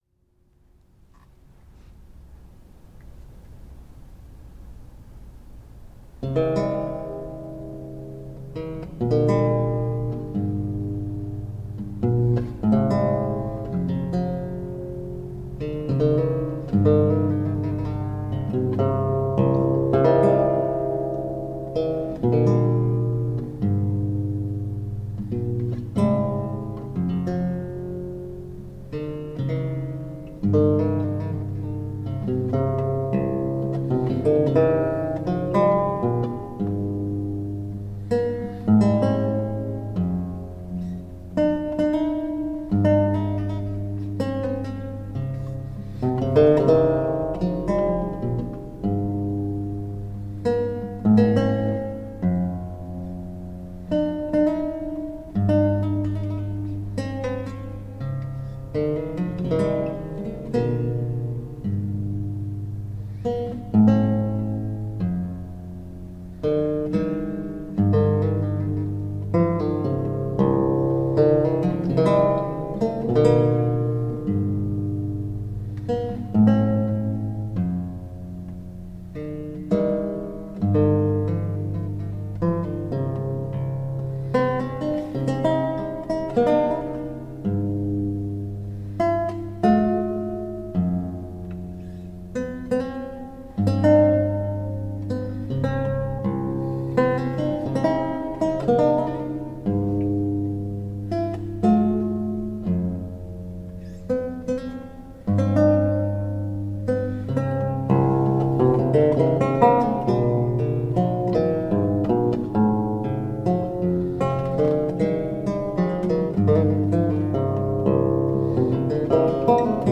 Reflective, historically-informed performance on the lute.
Classical, Renaissance, Baroque, Instrumental
Theorbo